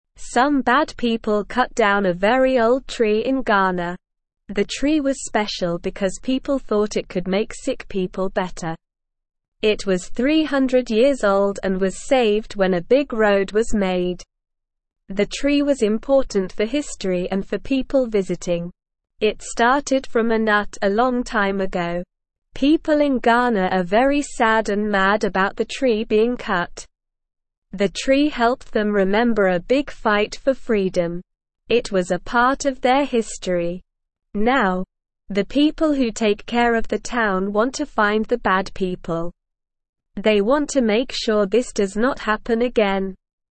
Slow
English-Newsroom-Beginner-SLOW-Reading-Old-Tree-in-Ghana-Cut-Down-by-Bad-People.mp3